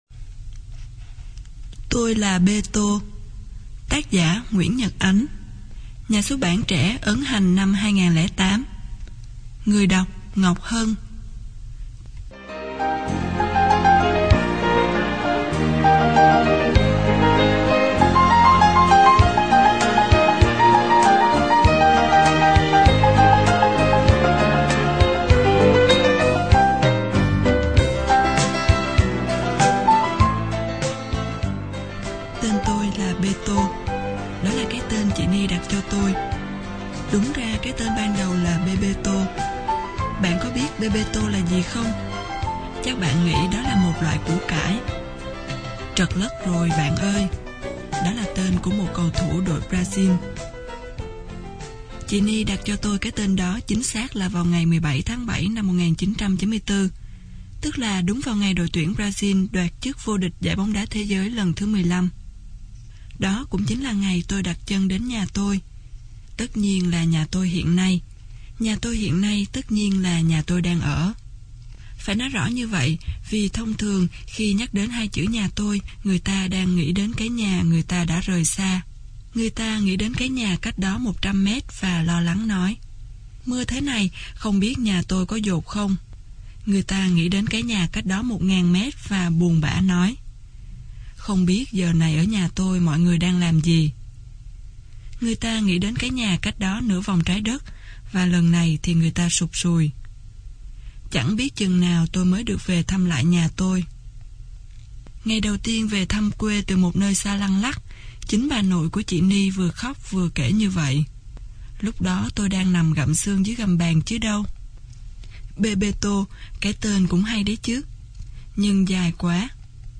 Sách nói Tôi Là Bê Tô - Nguyễn Nhật Ánh - Sách Nói Online Hay